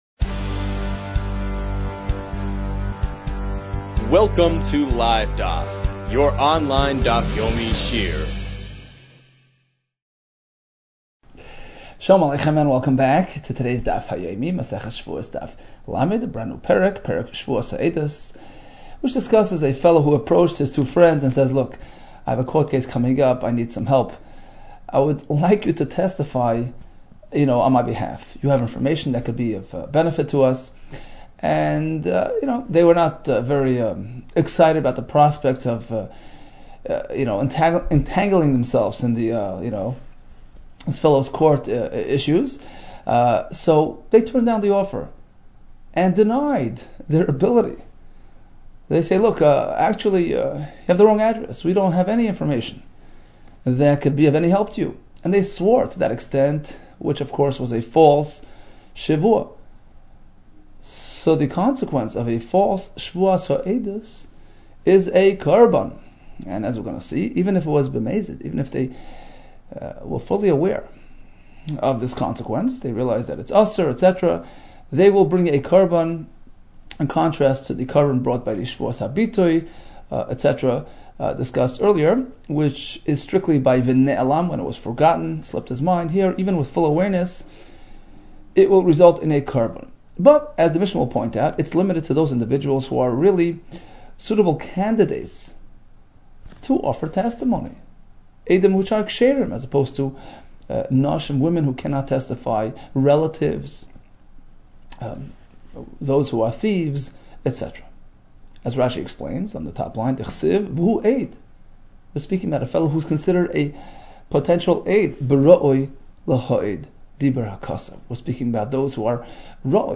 Shevuos 29 - שבועות כט | Daf Yomi Online Shiur | Livedaf